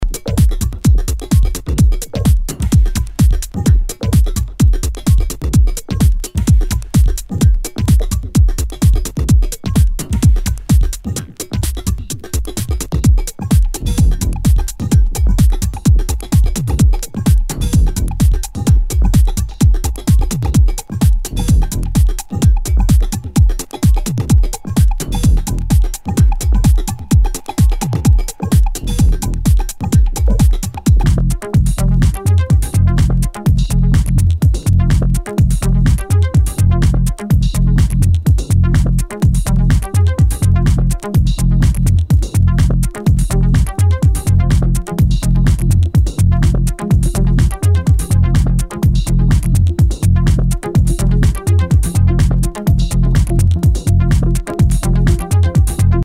HOUSE/TECHNO/ELECTRO
ナイス！エレクトロ・テック・ハウス！